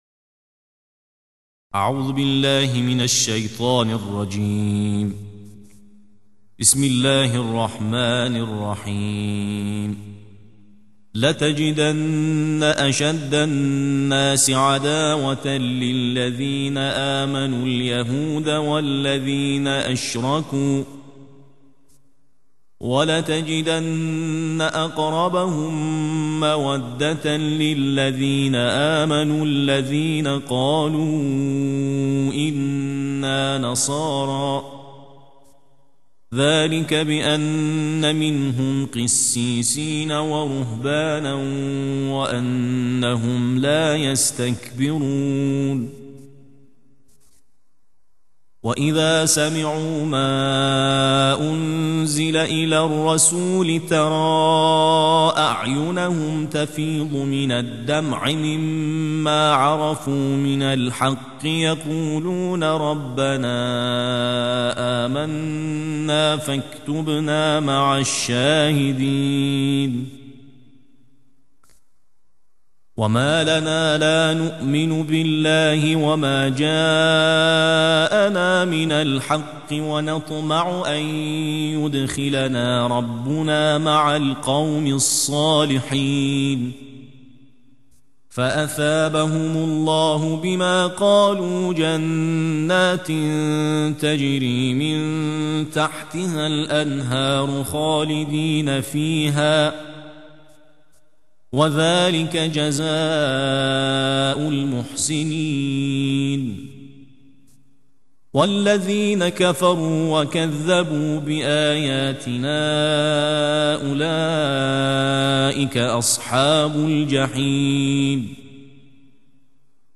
صوت/ ترتیل جزء هفتم قرآن